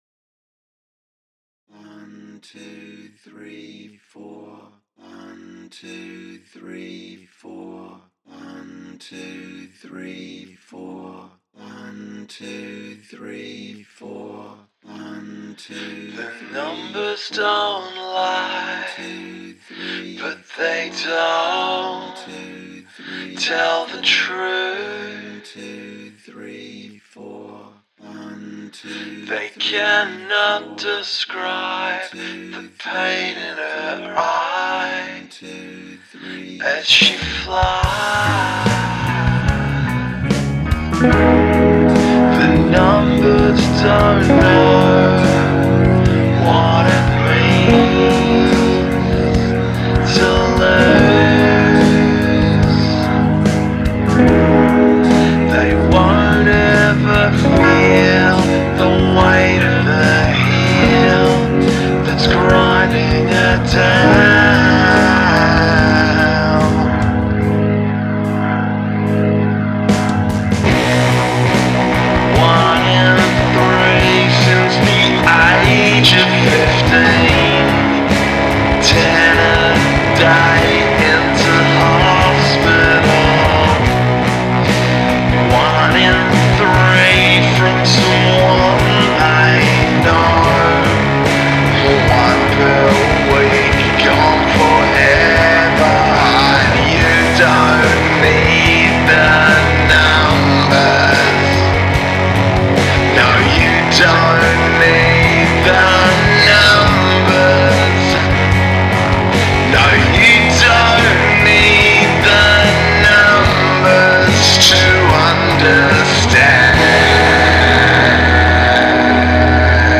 Use the Royal Road chord progression